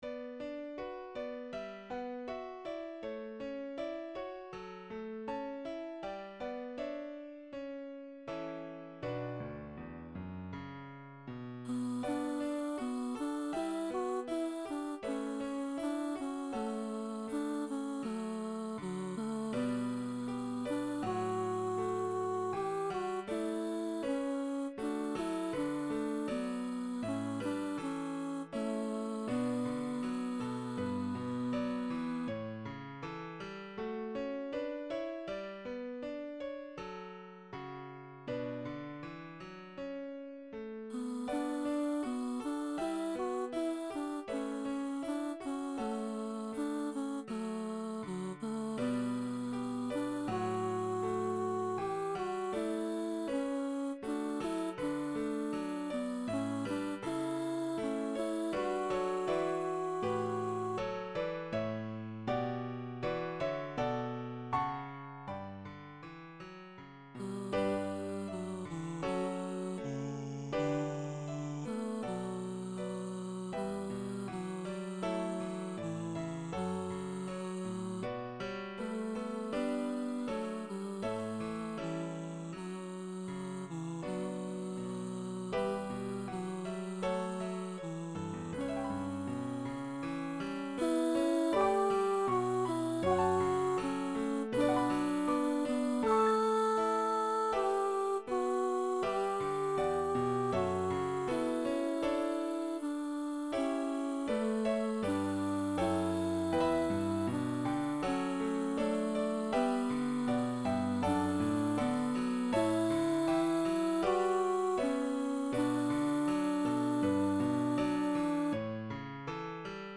High voice / piano